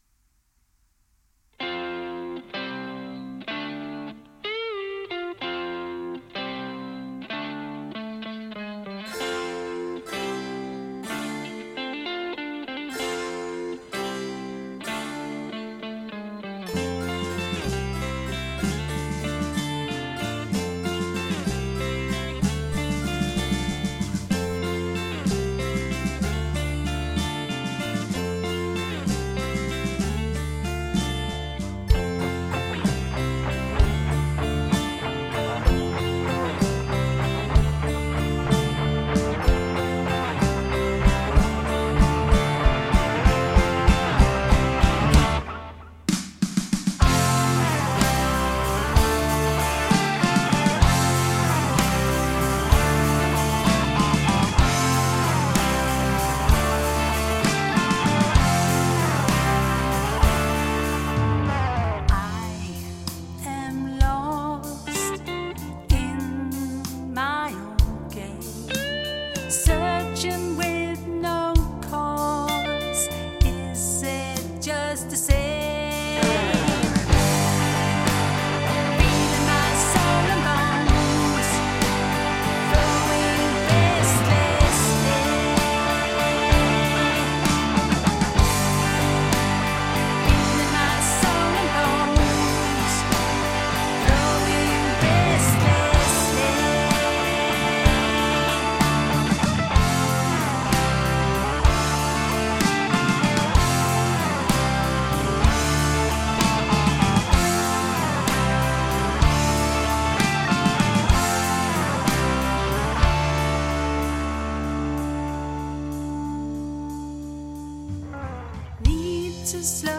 • Rock
Melodisk guitarrock